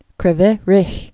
(krĭ-vĭ rĭ) or Kri·voi Rog or Kri·voy Rog (-voi rōg, rôk)